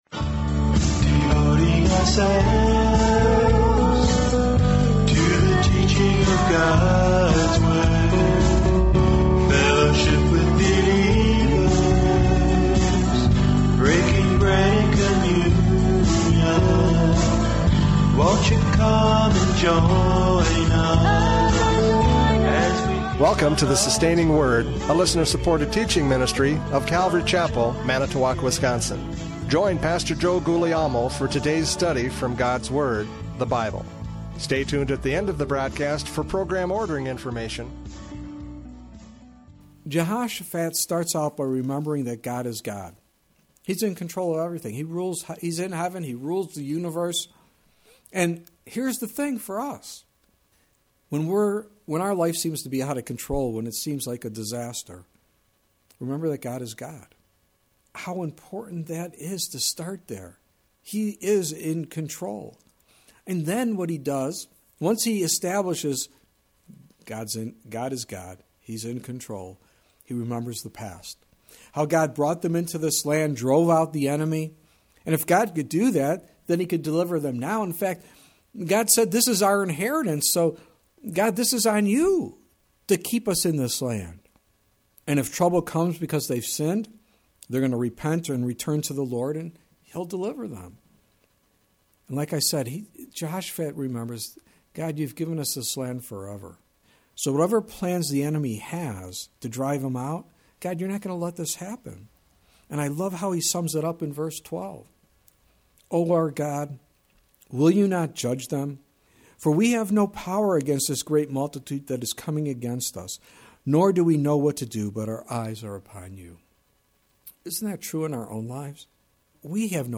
Radio Programs « Judges 5:13-18 “Who Will Go For Me?”